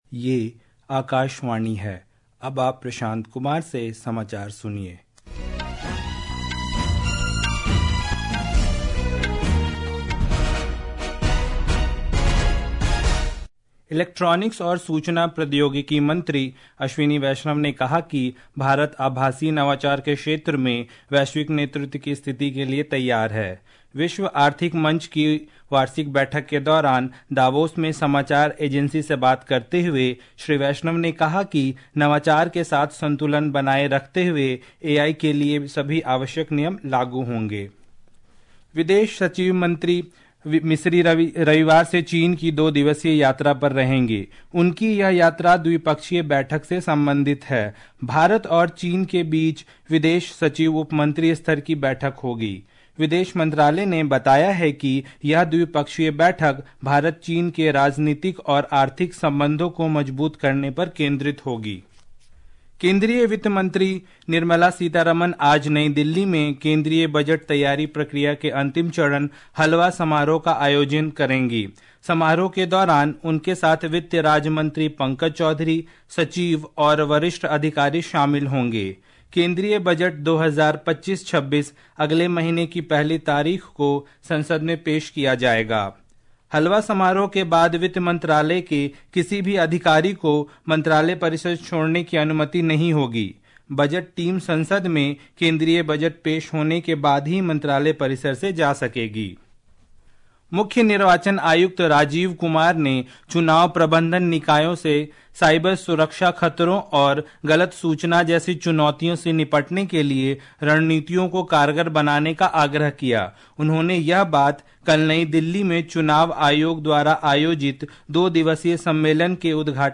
قومی بلیٹنز
प्रति घंटा समाचार